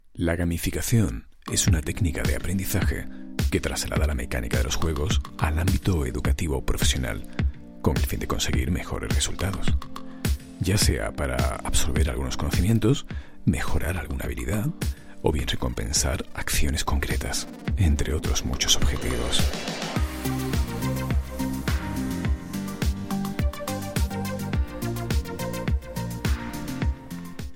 voz cálida, envolvente, elegante, transmite confianza, seguridad, seriedad con sentido de humor, versátil, seductora
Sprechprobe: eLearning (Muttersprache):
e-learning.mp3